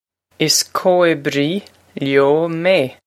Pronunciation for how to say
Iss co-ib-ree lyo may.